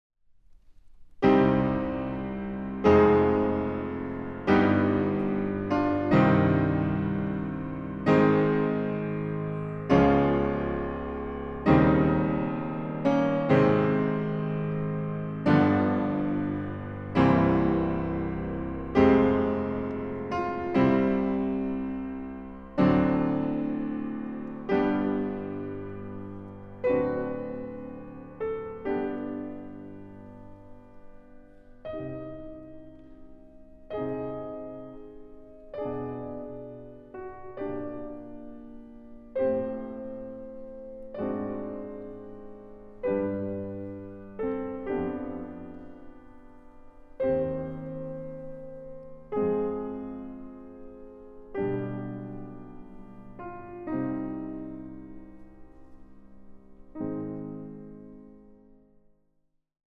Piano
Recording: Großer Saal, Gewandhaus Leipzig, 2025